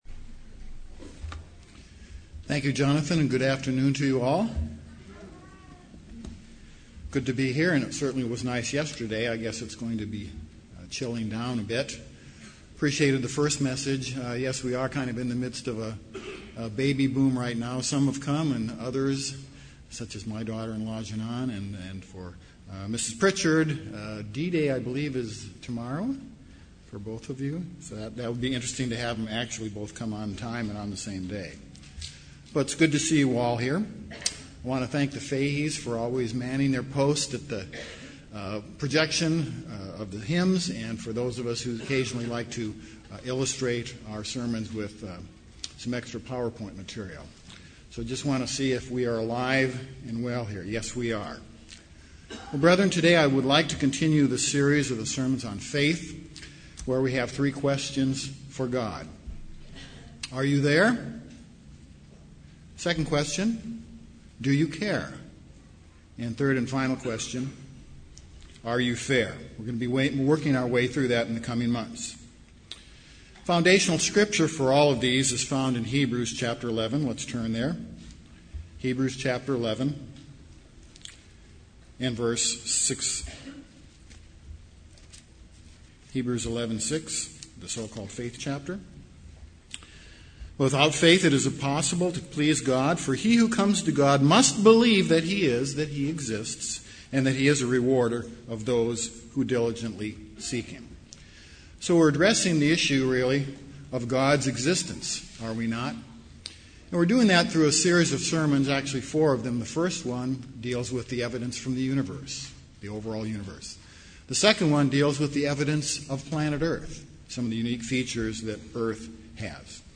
Given in Dallas, TX
UCG Sermon Studying the bible?